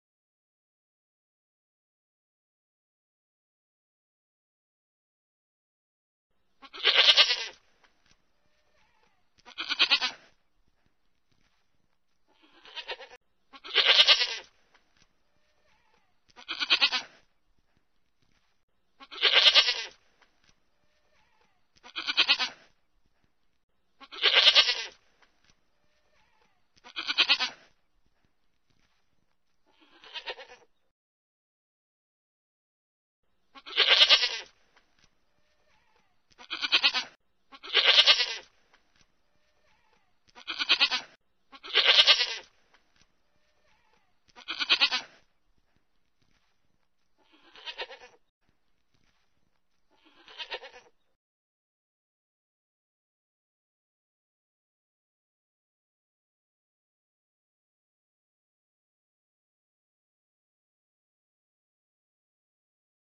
SONIDO DE LA CABRA.mp3